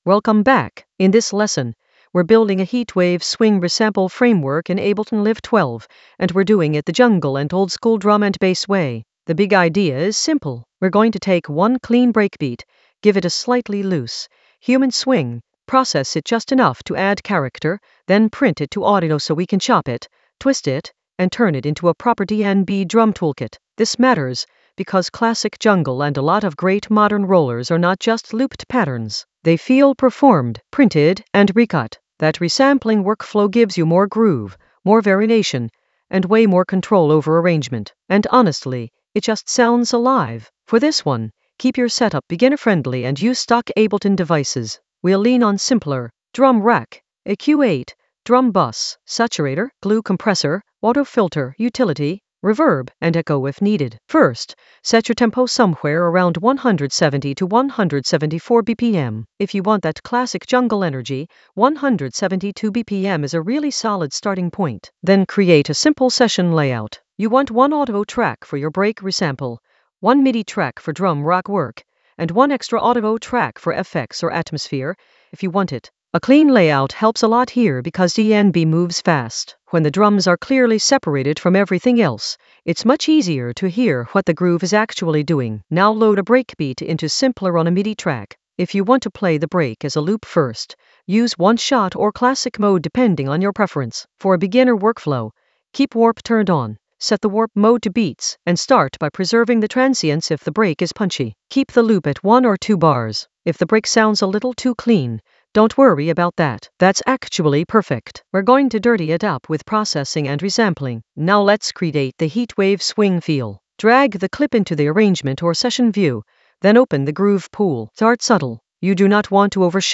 An AI-generated beginner Ableton lesson focused on Heatwave swing resample framework using resampling workflows in Ableton Live 12 for jungle oldskool DnB vibes in the Drums area of drum and bass production.
Narrated lesson audio
The voice track includes the tutorial plus extra teacher commentary.